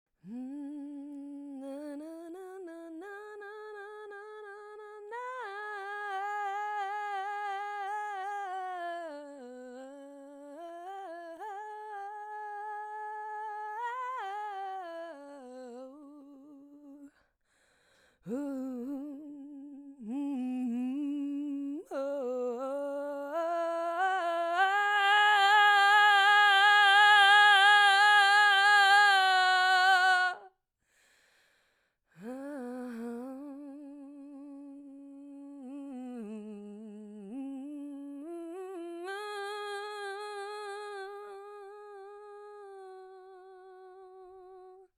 A Healing Voice Transmission to Soften, Expand, and Receive